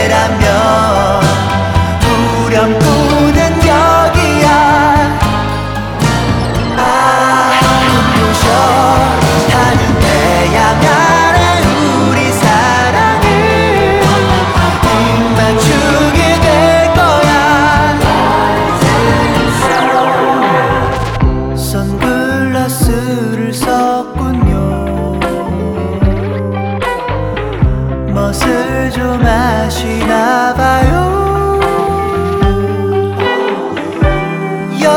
K-Pop Pop Rock
Жанр: Поп музыка / Рок